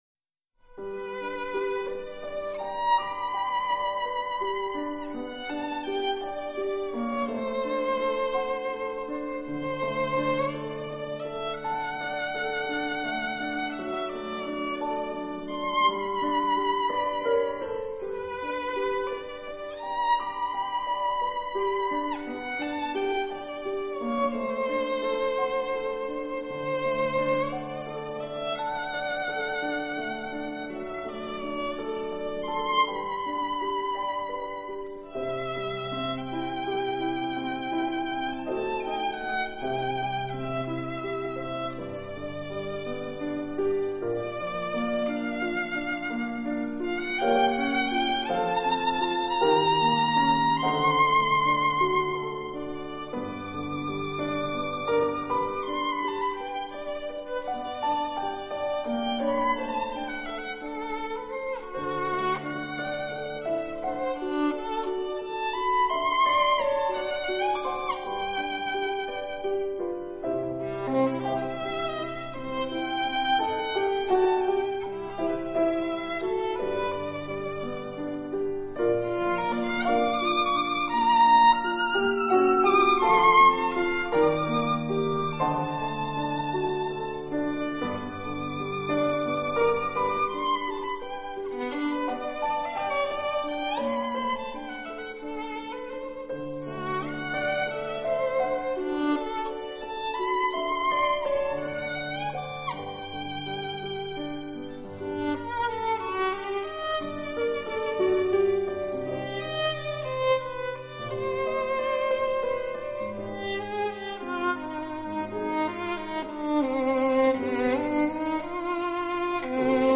音乐类型：小提琴演奏